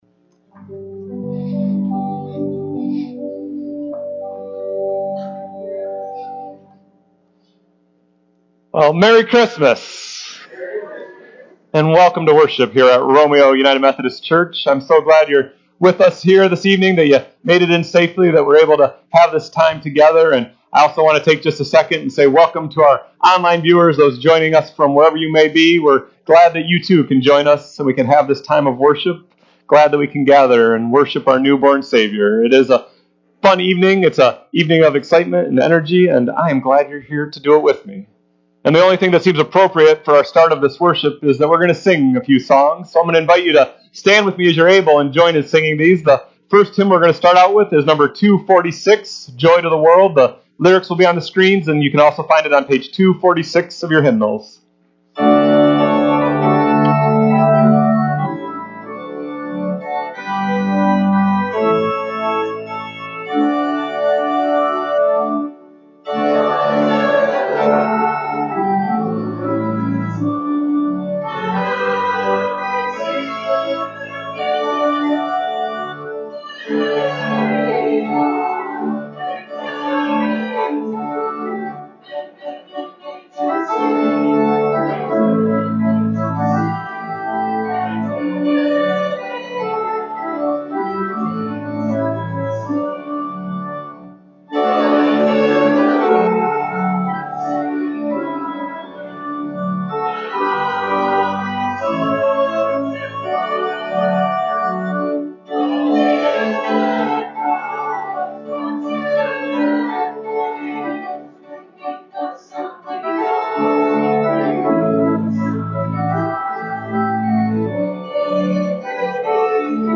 December 24, 2022 Christmas Eve Worship